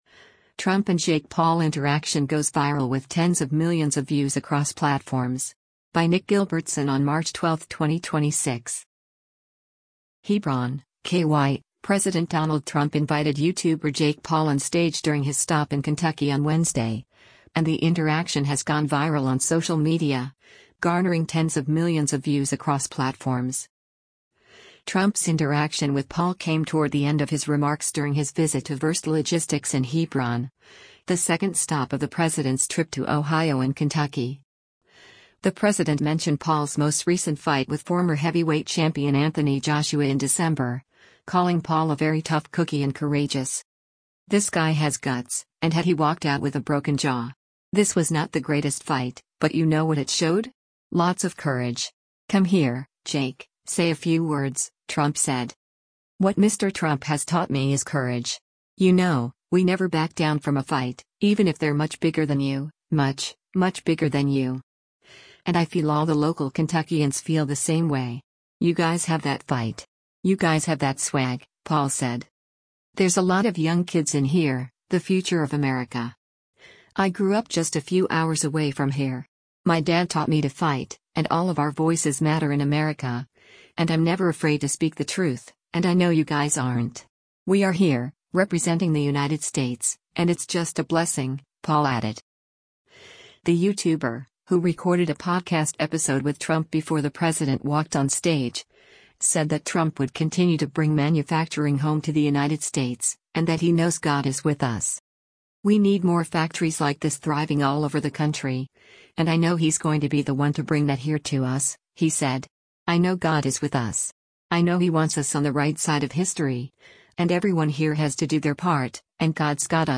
HEBRON, KY—President Donald Trump invited YouTuber Jake Paul on stage during his stop in Kentucky on Wednesday, and the interaction has gone viral on social media, garnering tens of millions of views across platforms.